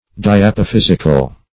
Diapophysical \Di*ap`o*phys"ic*al\